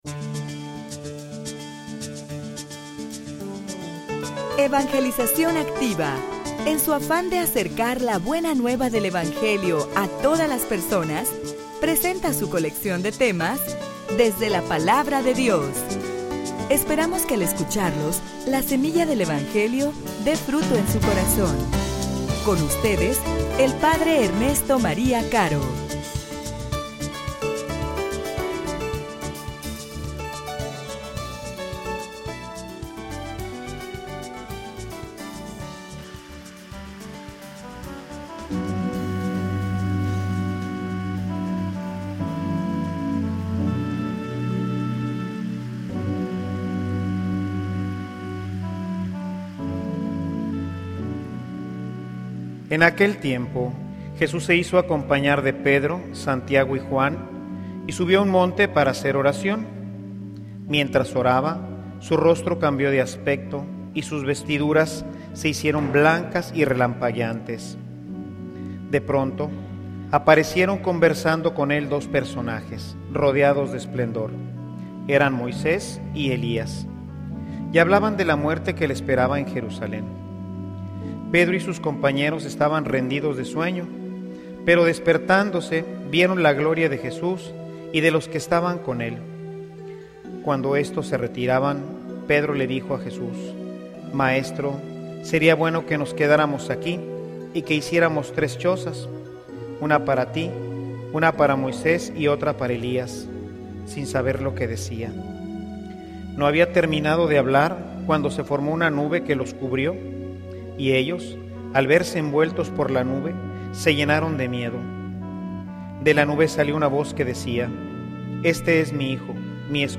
homilia_En_la_oracion_se_manifiesta_Dios.mp3